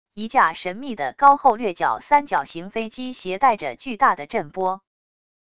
一架神秘的高后掠角三角形飞机携带着巨大的震波wav音频